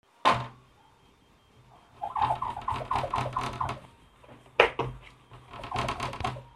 Knock knock » Knock 3x slow
描述：Knocking on a wooden table variations
标签： desk hit knock knocking slam table wood
声道立体声